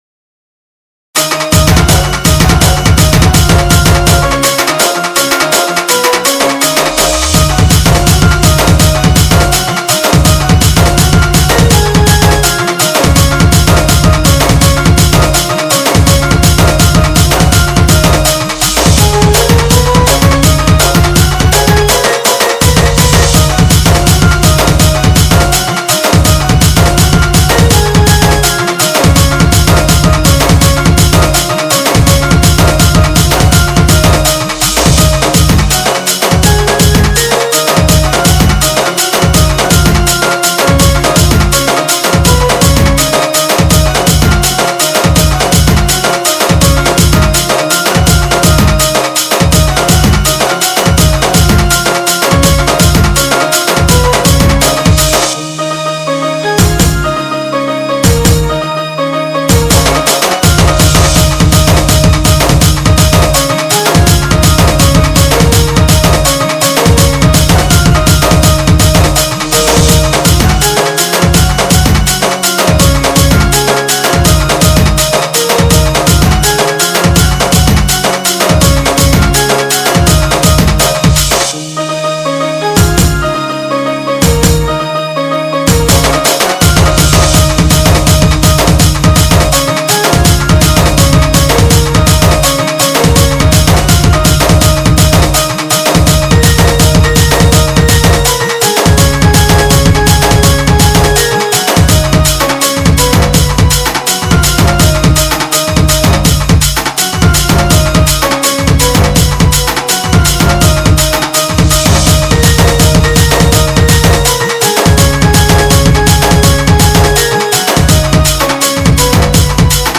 SAMBALPURI INSTRUMENT DJ REMIX